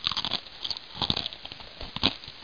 00943_Sound_crunch.mp3